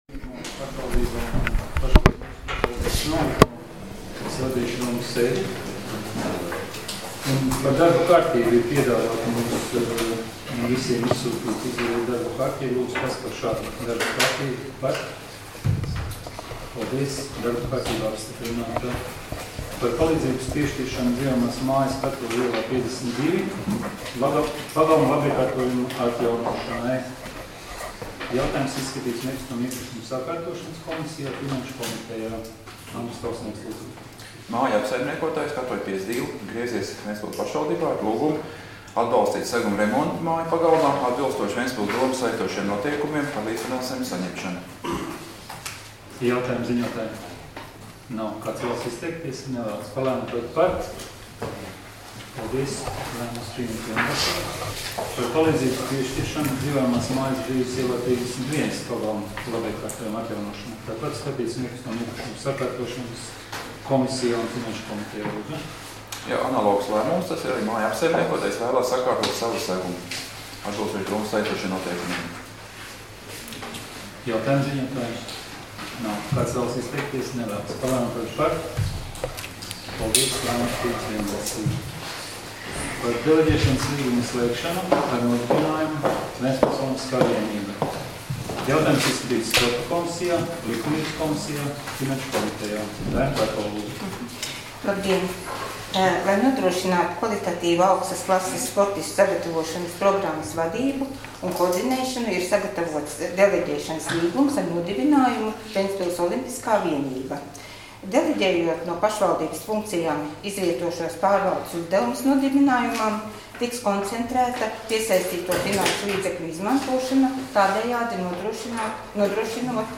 Domes sēdes 03.08.2018. audioieraksts